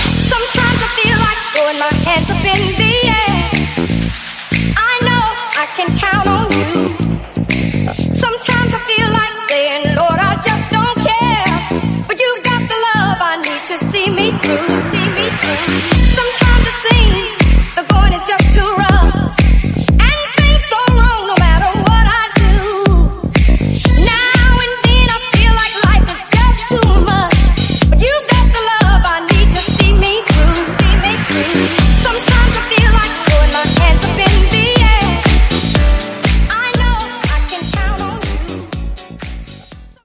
Dj Intro Outro
Genres: 80's , RE-DRUM , TOP40